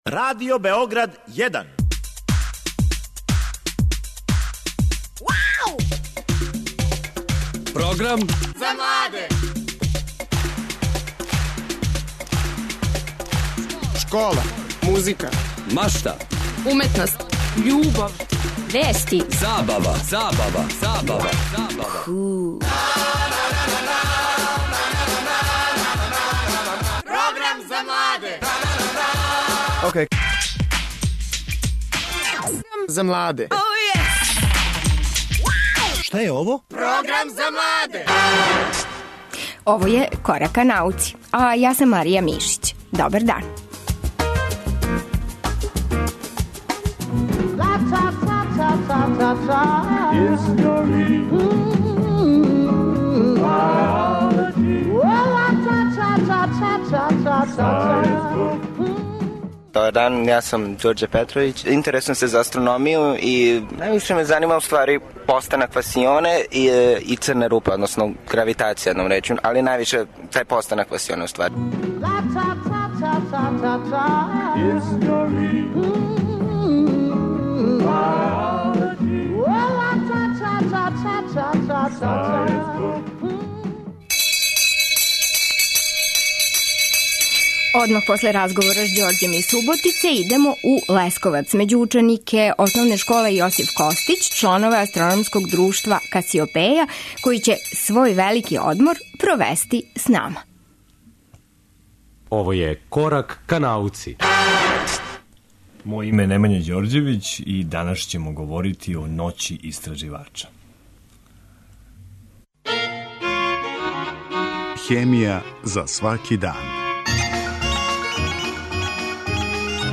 На великом одмору идемо у Лесковац, међу чланове астрономског друшта Касиопеја.